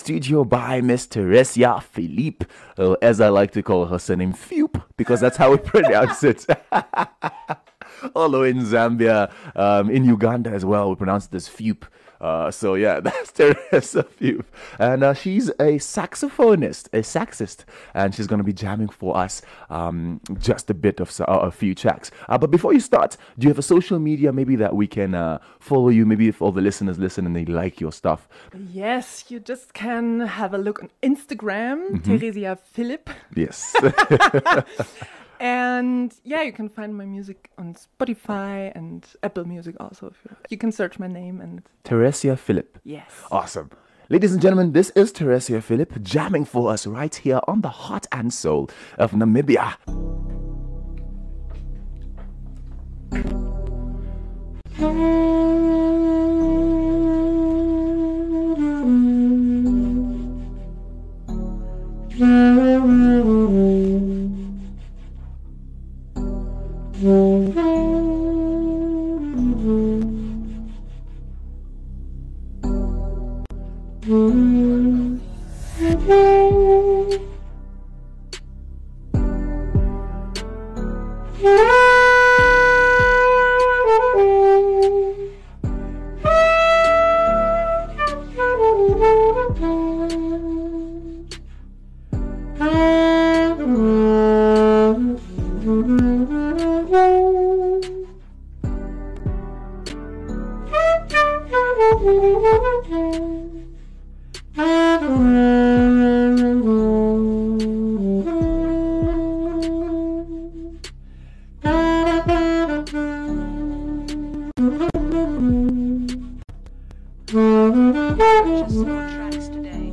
saxophonist
Live session in studio
German Saxophonist